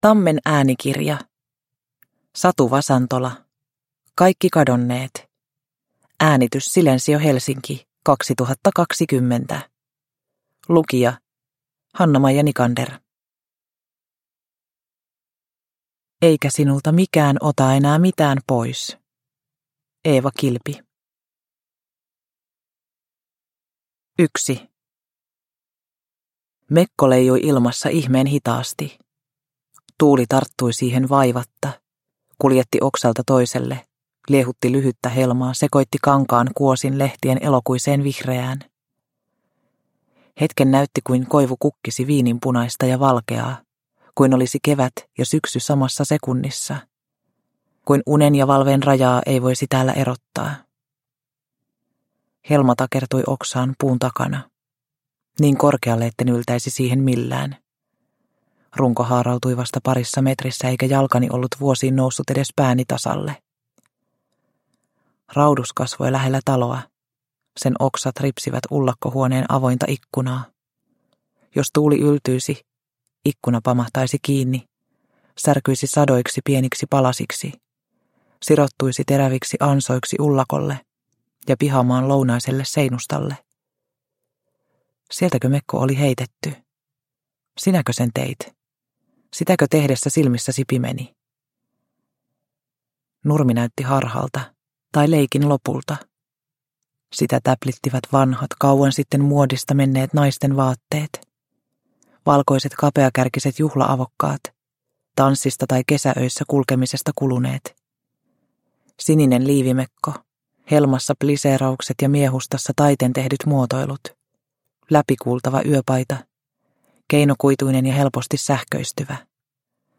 Kaikki kadonneet – Ljudbok – Laddas ner